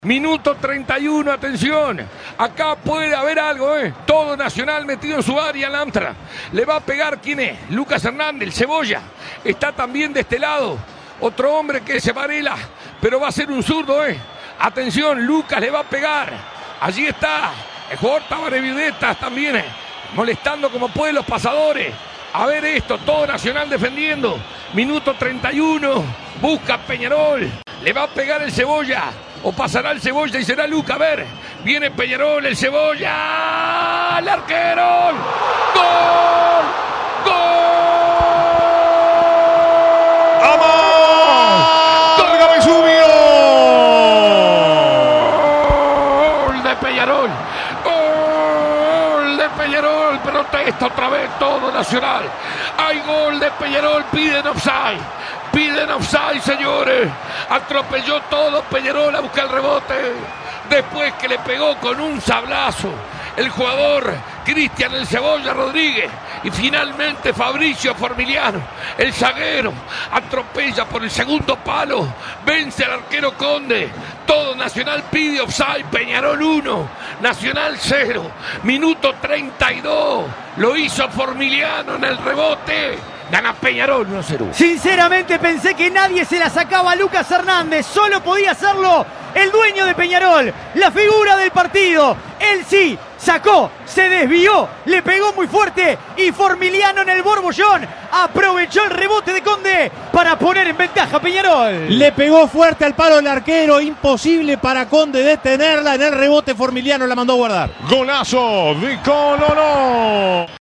Reviví los goles con el relato de Alberto Sonsol por El Espectador.
Cancha: Estadio Centenario. Público: 35.000 personas.